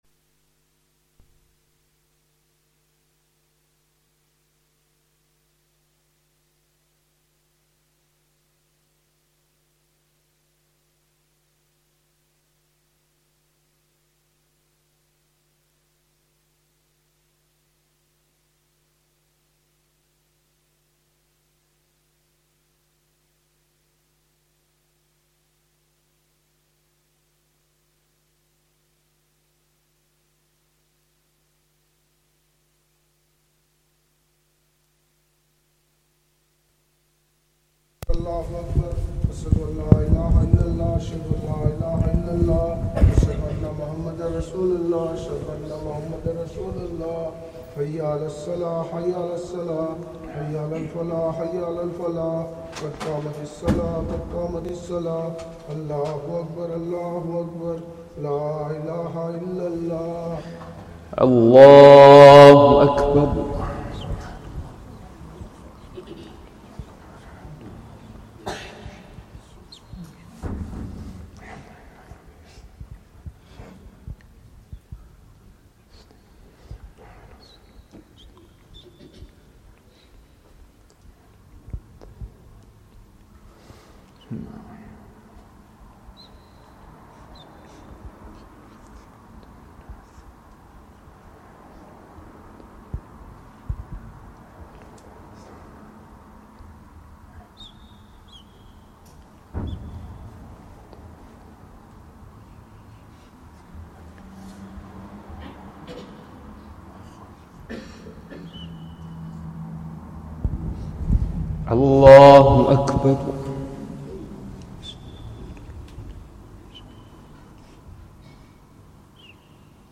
Arab Recitation